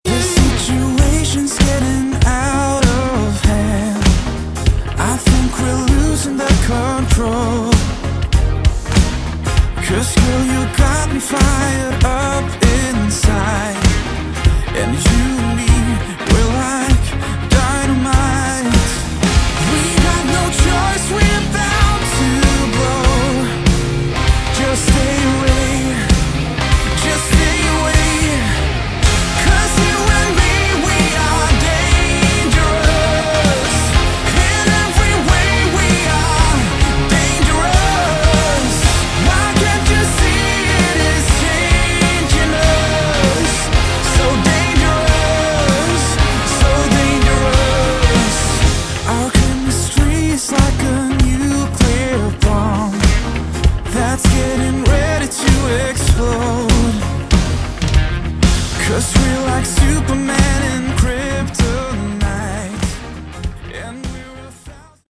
Category: AOR / Melodic Rock
lead vocals
lead guitar
rhythm guitar
keyboards
bass
drums